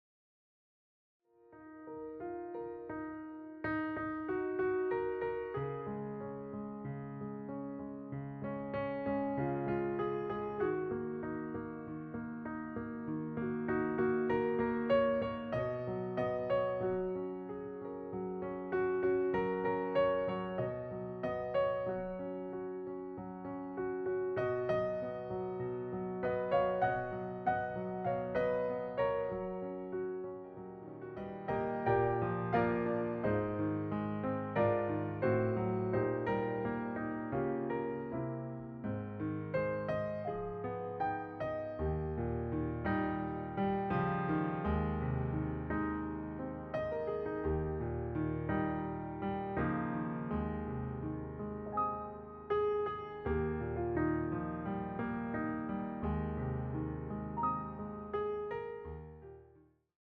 piano interpretations